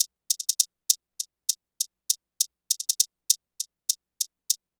Electric Chickyshake.wav